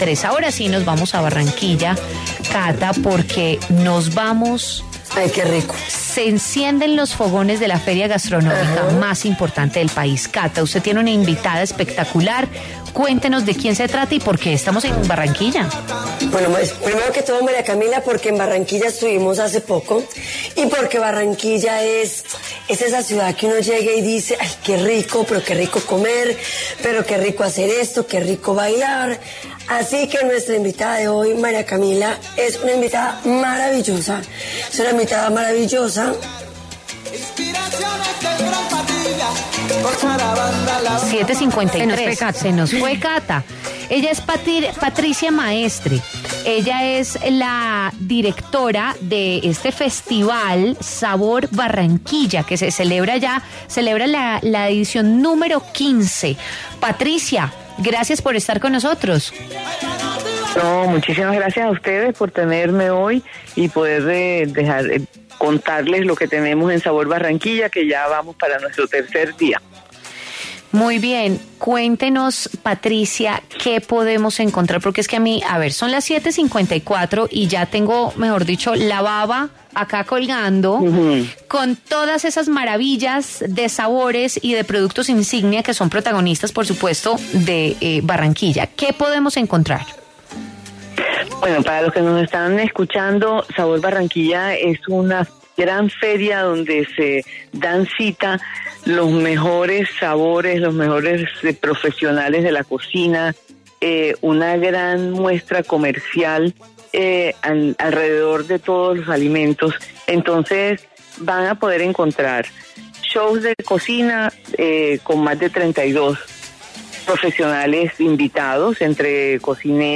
En entrevista con W Fin De Semana